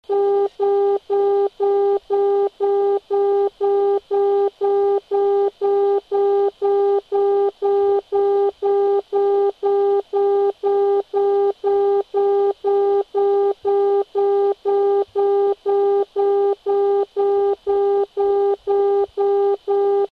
TV - Outer Marker heard on 75 MHz: (138kb)